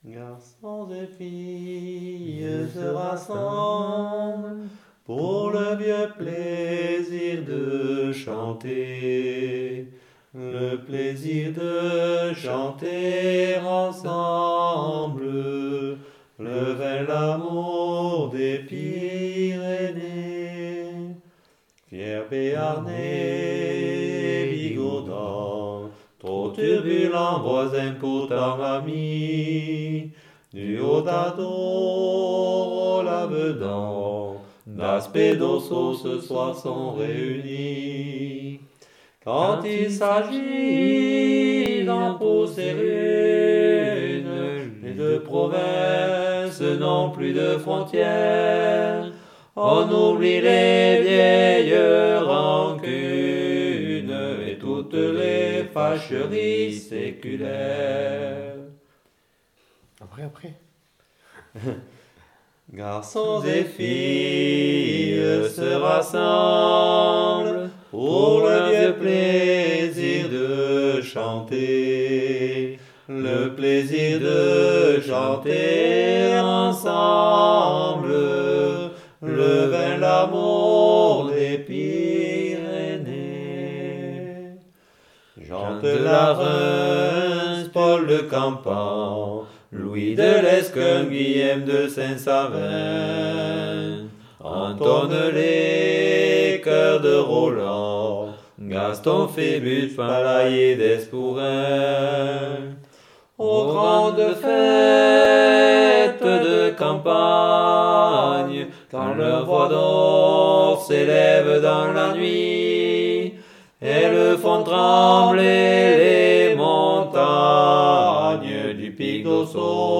Aire culturelle : Bigorre
Lieu : Bénac
Genre : chant
Effectif : 2
Type de voix : voix d'homme
Production du son : chanté
Descripteurs : polyphonie
Classification : chanson identitaire